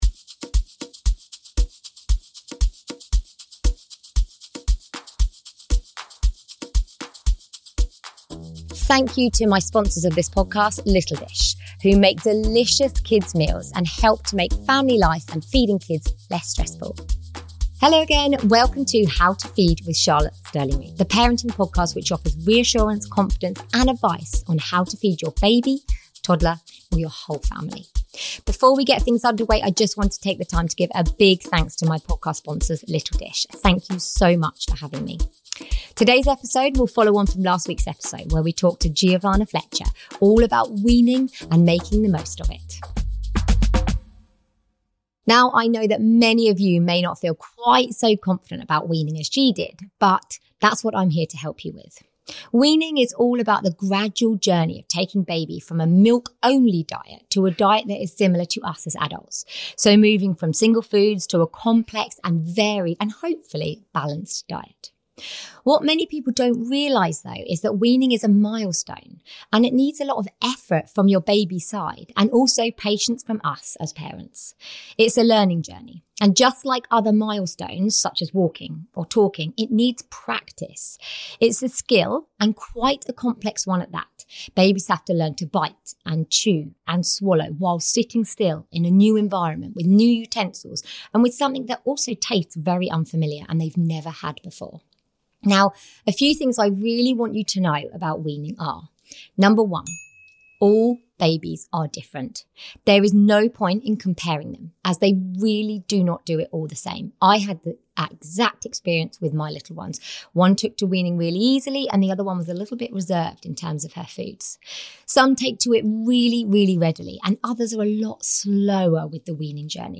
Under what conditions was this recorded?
This podcast is sponsored by Little Dish and recorded at their HQ.